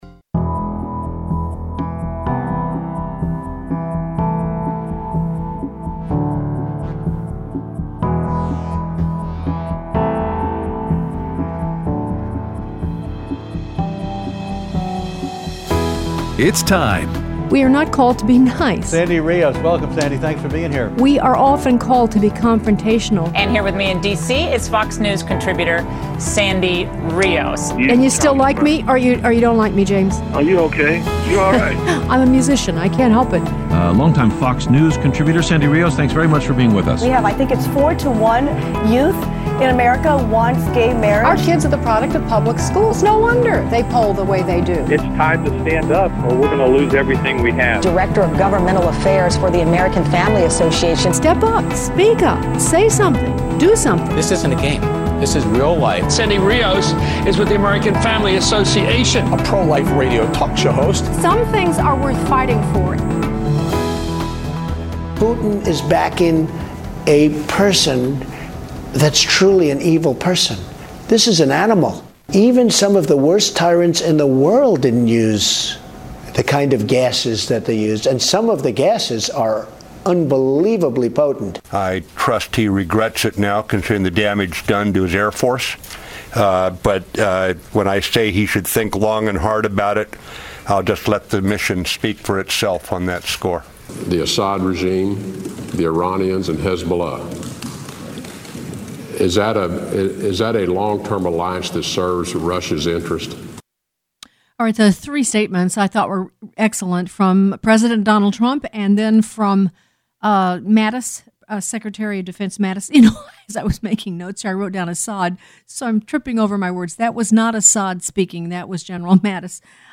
Aired Wednesday 4/12/17 on AFR 7:05AM - 8:00AM CST